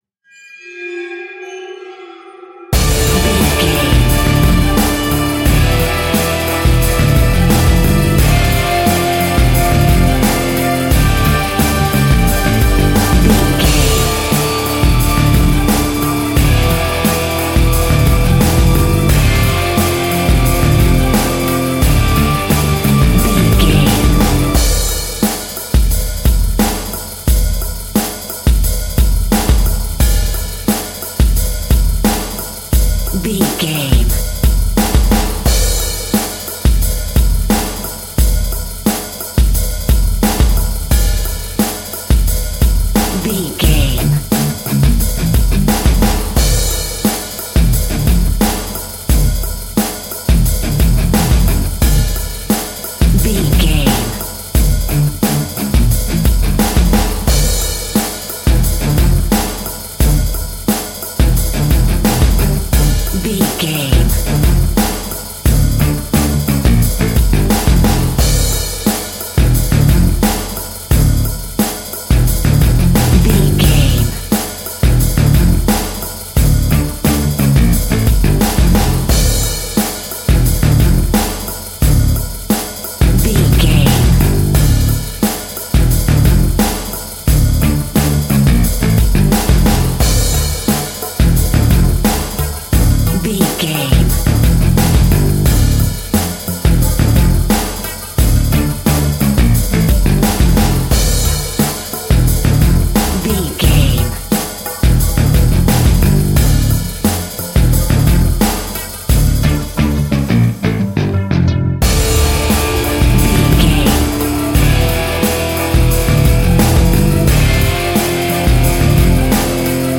In-crescendo
Aeolian/Minor
ominous
dark
suspense
eerie
staccato strings
Rock Drums
Rock Bass
Overdriven Guitar
Distorted Guitar